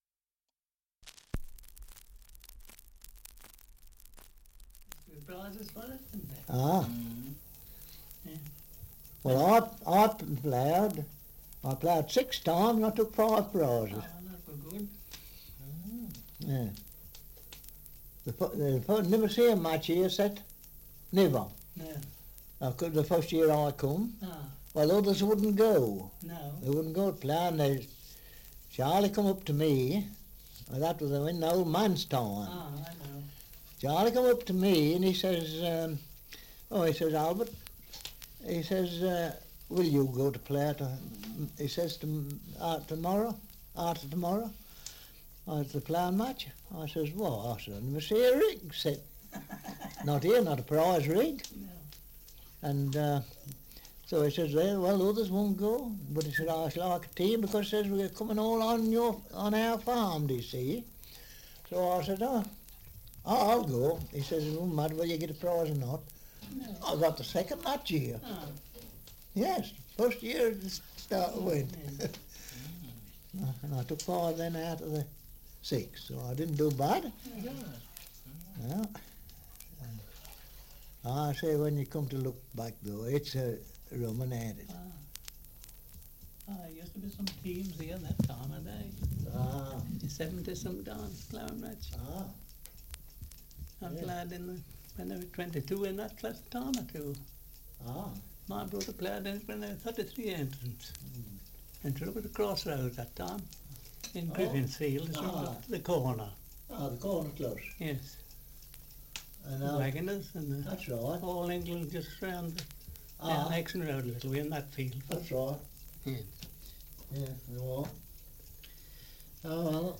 Survey of English Dialects recording in Empingham, Rutland
78 r.p.m., cellulose nitrate on aluminium